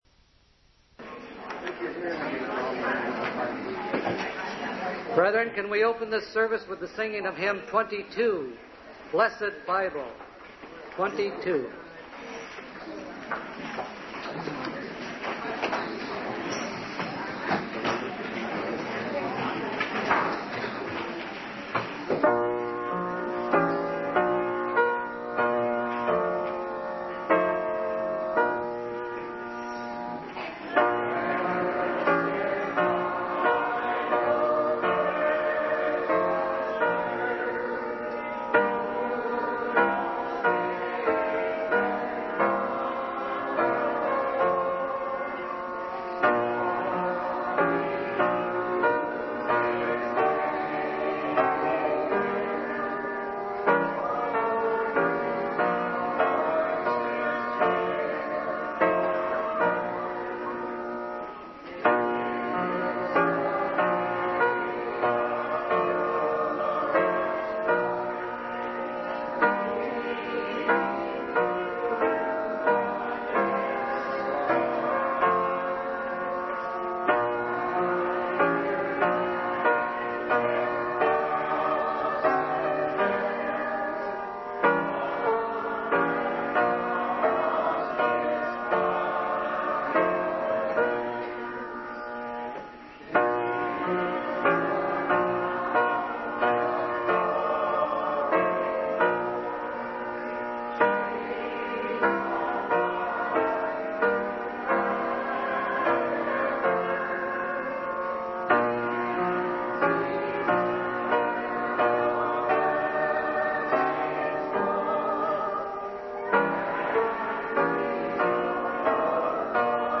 Vesper service